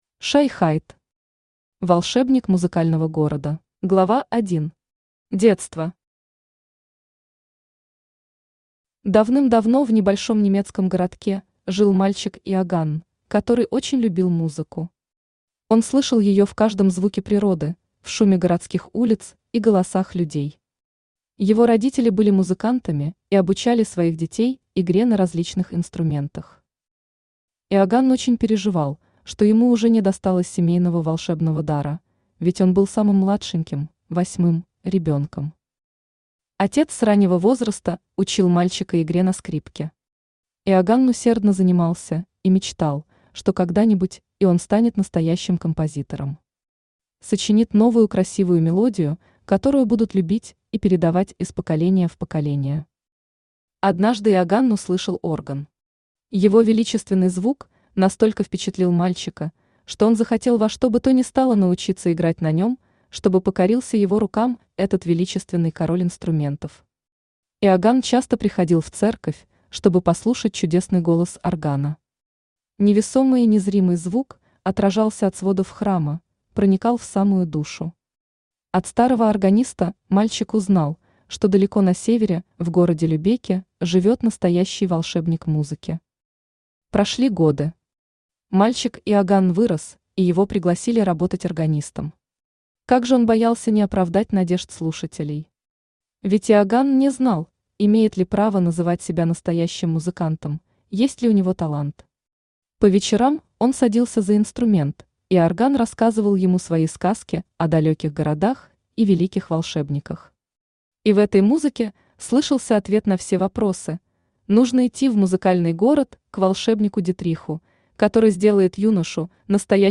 Аудиокнига Волшебник Музыкального города | Библиотека аудиокниг
Aудиокнига Волшебник Музыкального города Автор Shy Hyde Читает аудиокнигу Авточтец ЛитРес.